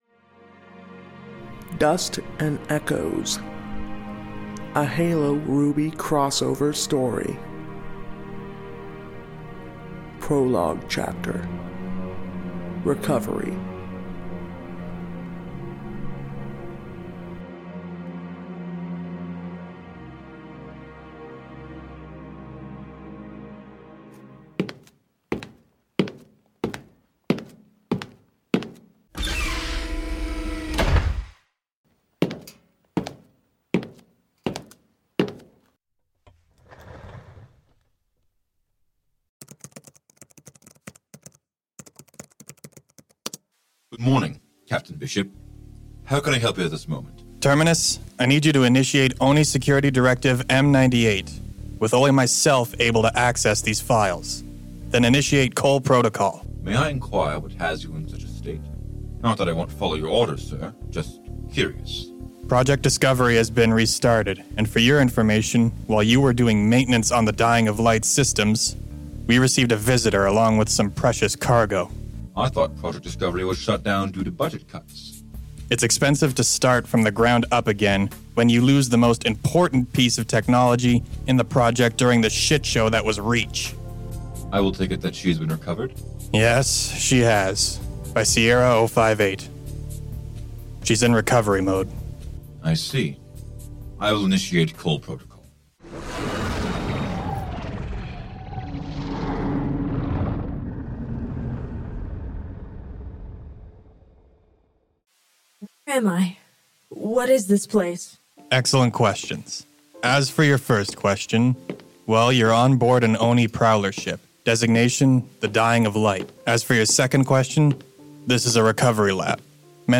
Exploring the Epic Crossover: Dust & Echoes – A Unique Audio Drama Experience